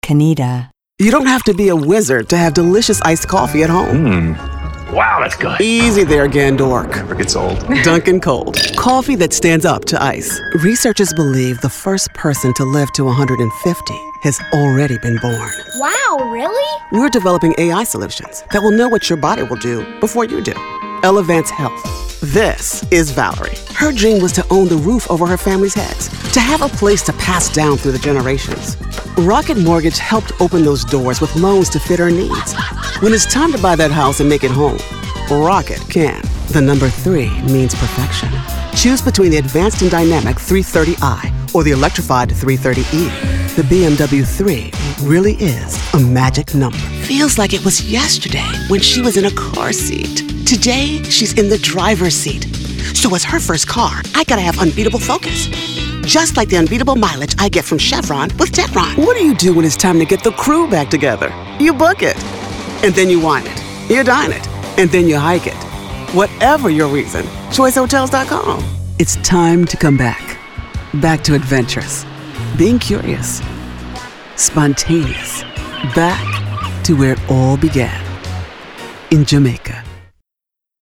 Showcase Demo African American, announcer, anti-announcer, attitude, authoritative, classy, compelling, confident, conversational, cool, corporate, english-showcase, friendly, genuine, inspirational, middle-age, midlife, mother, professional, promo, real, sincere, smooth, thoughtful, upbeat, warm